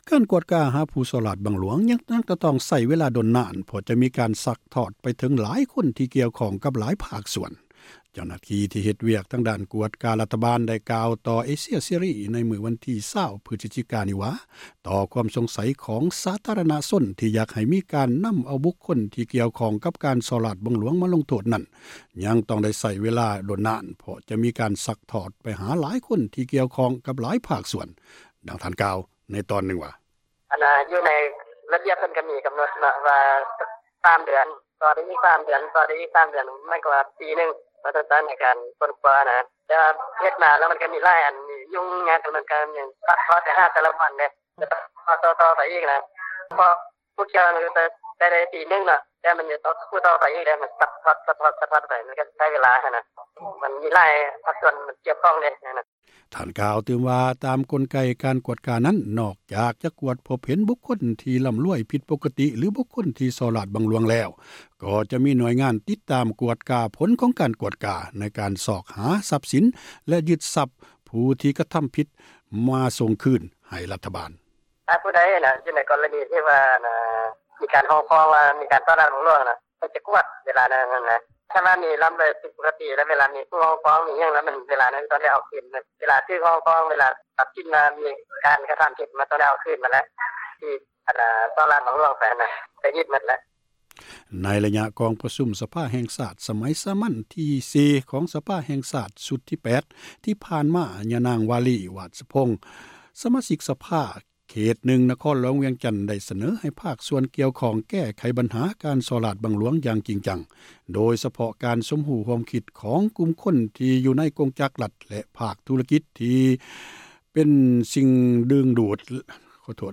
ເຈົ້າໜ້າທີ່ ດ້ານການກວດກາຣັຖບານ ໄດ້ກ່າວຕໍ່ຜູ້ສື່ຂ່າວເອເຊັຽເສຣີ ເມື່ອວັນທີ 20 ພຶສຈິກາ ນີ້ວ່າ ຕໍ່ຄວາມສົງໃສ ຂອງສາທາຣະນະຊົນ ທີ່ຢາກໃຫ້ມີການນຳເອົາ ບຸກຄົນທີ່ກ່ຽວຂ້ອງ ກັບການສໍ້ຣາສບັງຫຼວງ ມາລົງໂທສນັ້ນ ຍັງຕ້ອງໃຊ້ເວລາຕື່ມອີກດົນ ເພາະຈະມີການຊັກທອດ ໄປຫາຫລາຍຄົນທີ່ກ່ຽວຂ້ອງ ກັບຫລາຍພາກສ່ວນ, ດັ່ງທ່ານທີ່ໄດ້ກ່າວ ໃນຕອນນຶ່ງວ່າ: